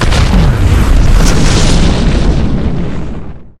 combat / vehicles / rocketo.ogg